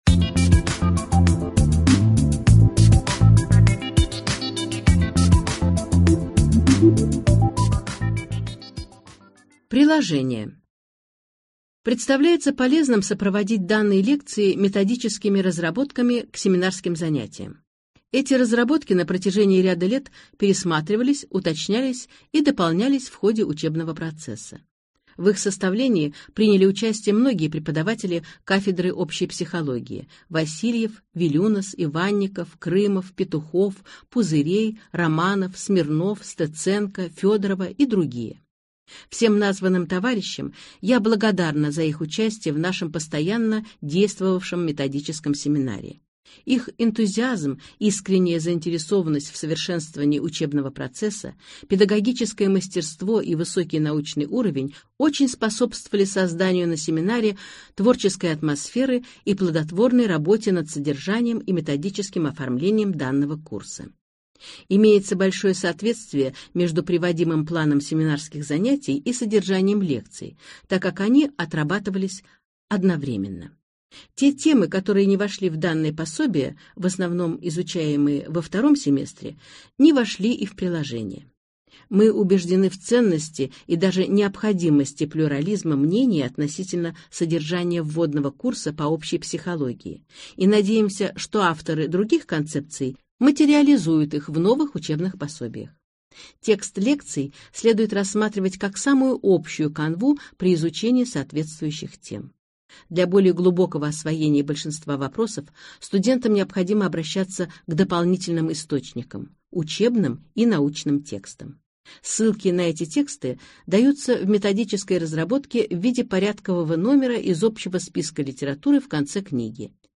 Аудиокнига Введение в общую психологию | Библиотека аудиокниг